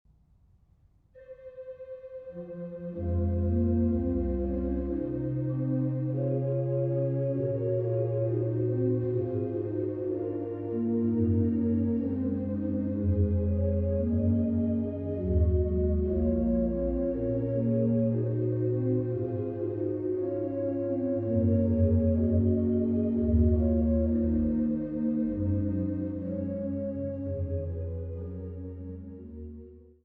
à 2 Clav. et Ped., in Canone alla Duodecima